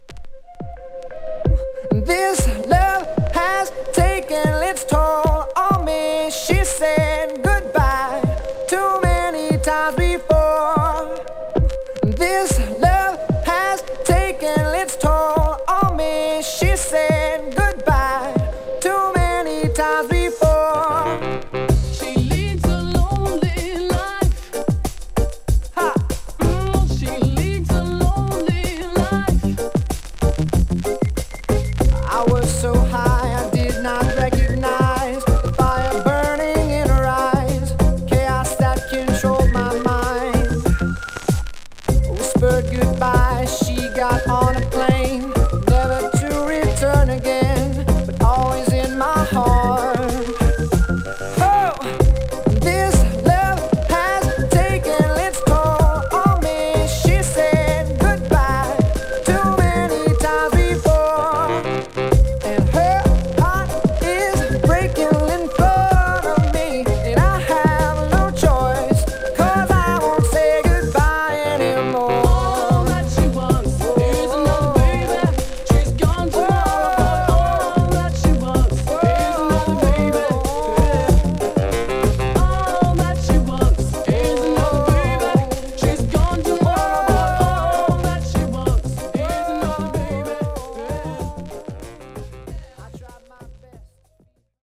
HIP HOP/R&Bリスナー直撃の極上のヤバすぎるブレンド集！